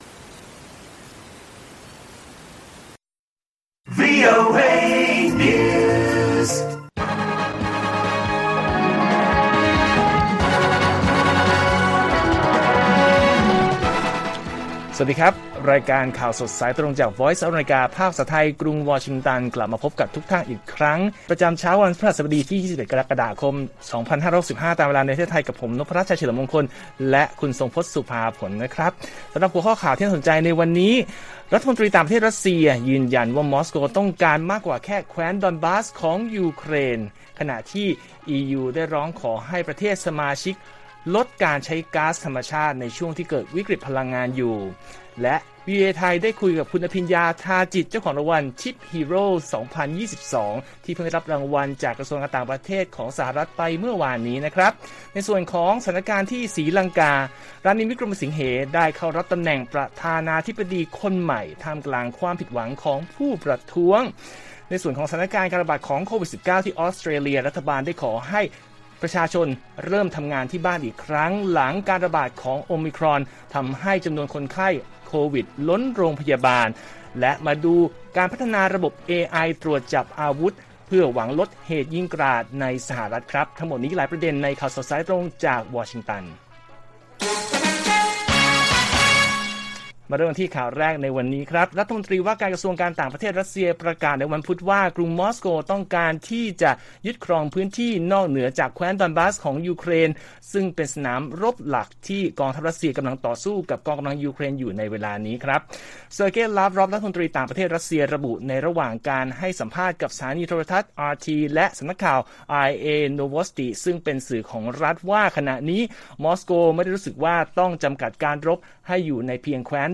ข่าวสดสายตรงจากวีโอเอไทย 8:30–9:00 น. วันที่ 20 ก.ค. 65